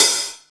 paiste hi hat9 half.wav